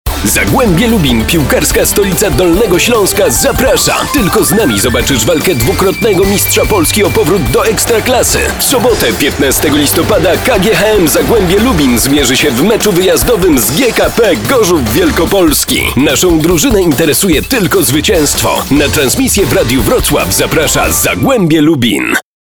Professioneller polnischer Sprecher für TV / Rundfunk/Industrie.
Kein Dialekt
Sprechprobe: eLearning (Muttersprache):